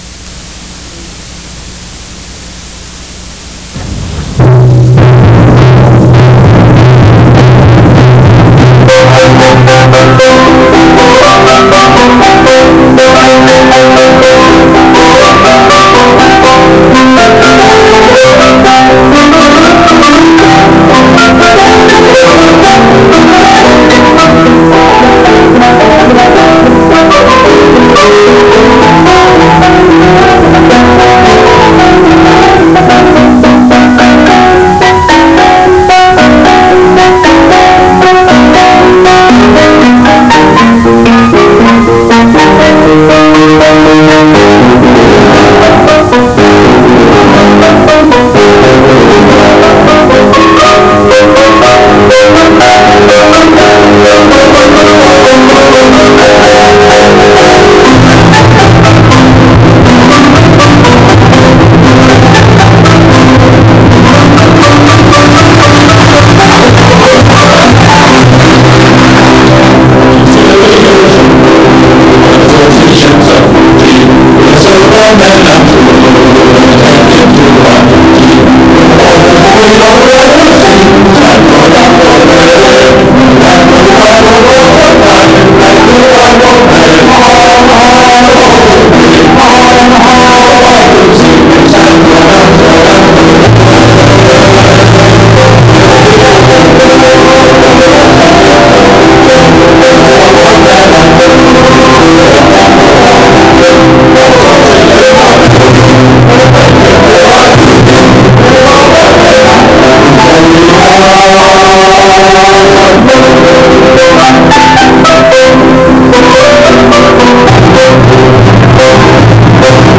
(Harrow County School production, 1967)